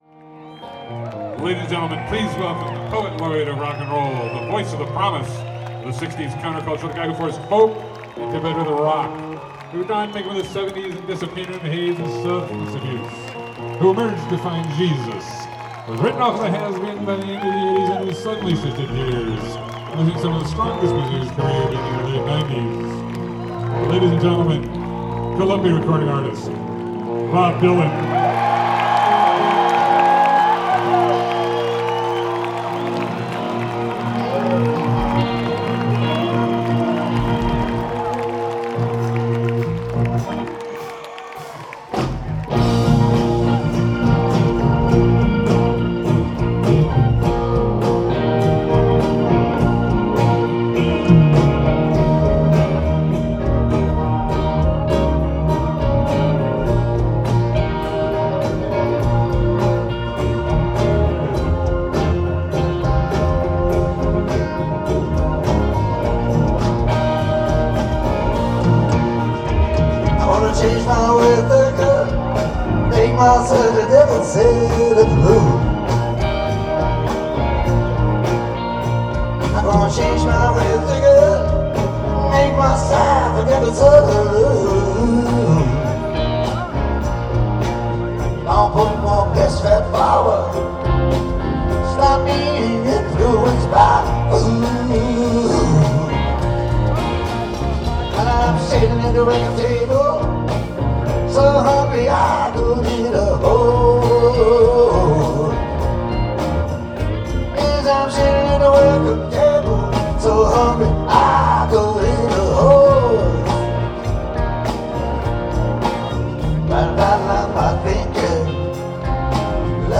The Pacific Amphitheatre - Costa Mesa, CA